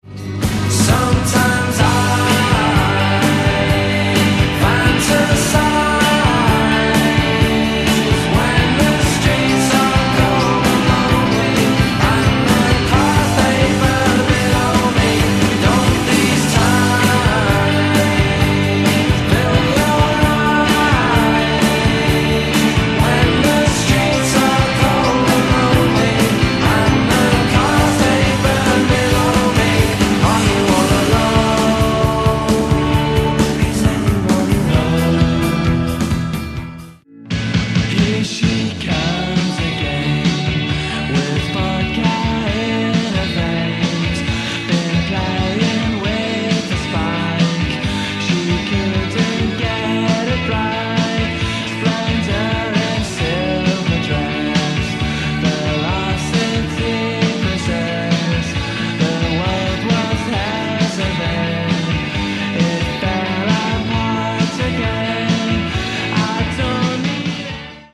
brilliantly dark
jangly